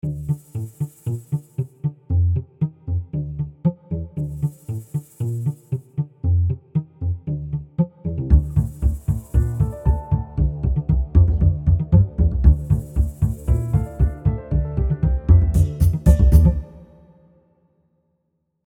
dance/electronic
Ambient